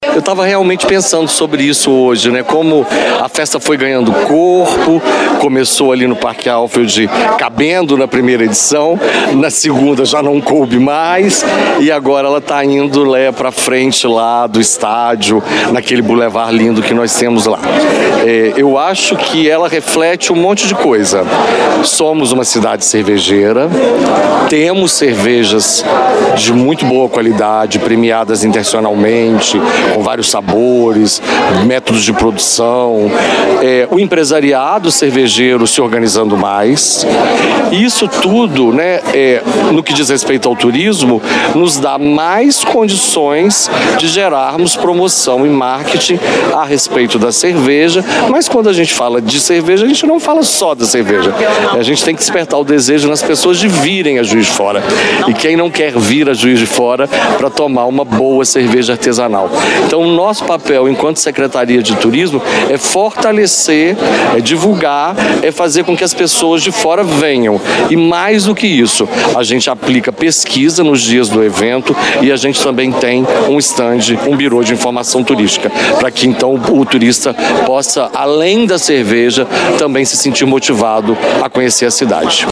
Em um evento na noite de quarta-feira (9) no Seminário da Floresta, representantes dos setores envolvidos falaram sobre a importância da produção cervejeira na cidade, as novidades e a expectativa para este ano.
O secretário de Turismo de Juiz de Fora, Marcelo do Carmo, fala sobre a escalada de crescimento do evento.